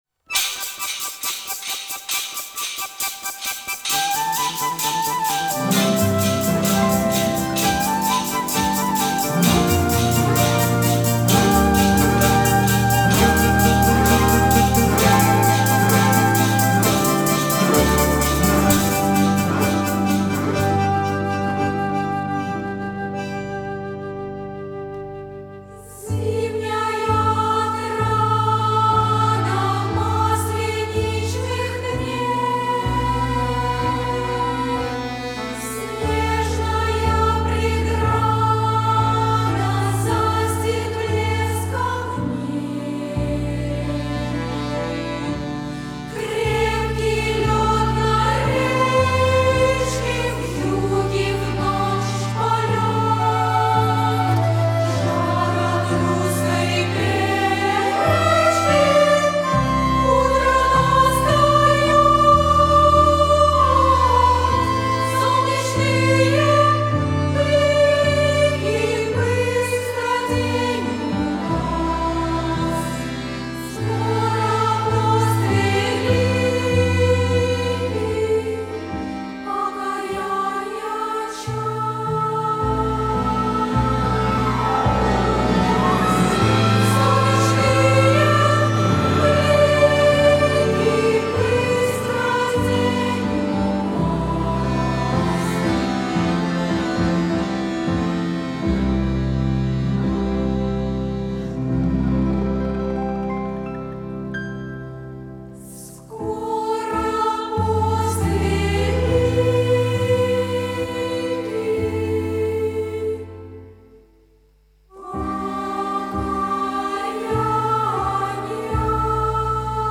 Исполняет концертный хор